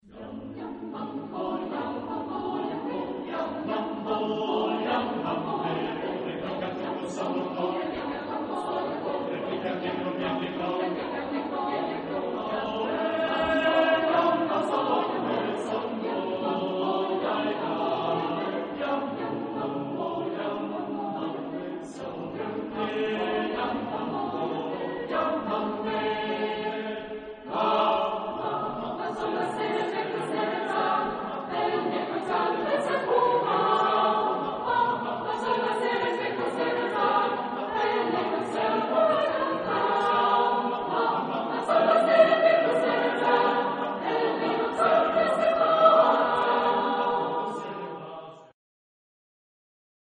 Genre-Style-Form: Secular ; Rhythmic
Mood of the piece: joyous ; rhythmic ; lively
Type of Choir: SATBB  (5 mixed voices )
Tonality: A major